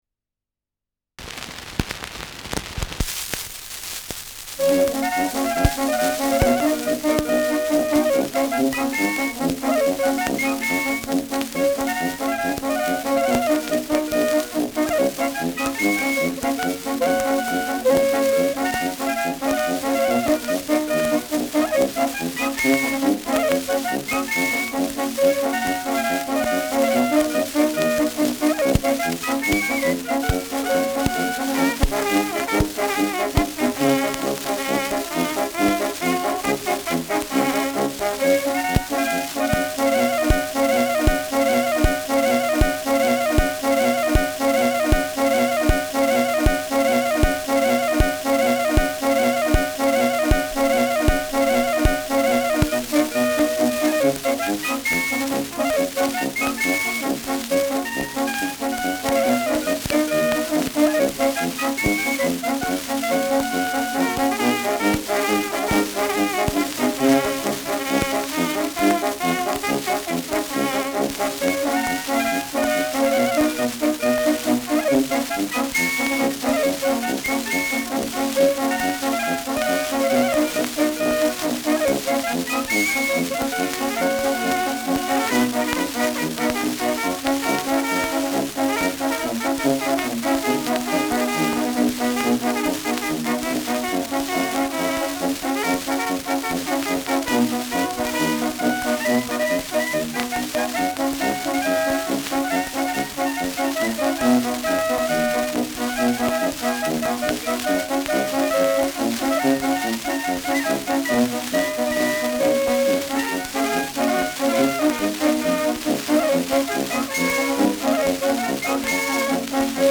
Schellackplatte
Stärkeres Grundrauschen : Nadelgeräusch : Gelegentlich leichtes bis starkes Knacken : Verzerrt an lauteren Stellen : Springt und hängt im ersten Drittel